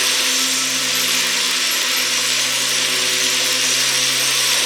shimmer_magic_burn_loop_01.wav